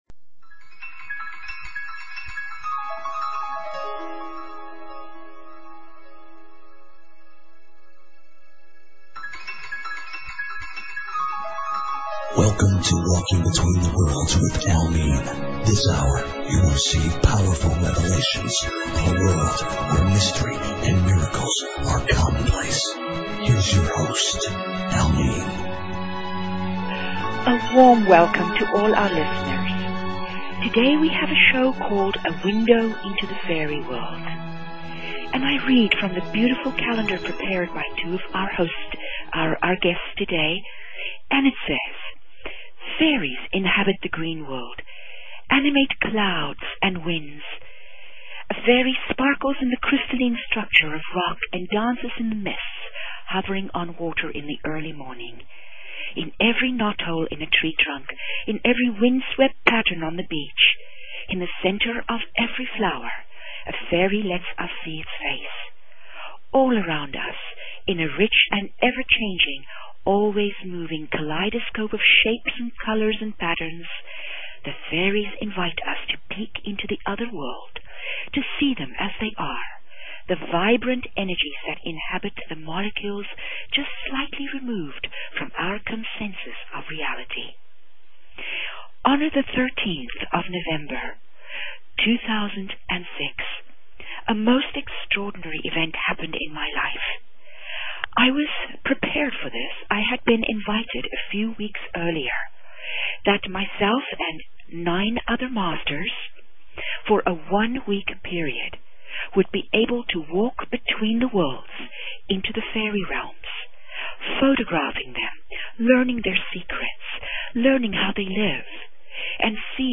Talk Show Episode, Audio Podcast, Secrets_of_the_Hidden_Realms and Courtesy of BBS Radio on , show guests , about , categorized as
These interviews are powerful enough to change your life!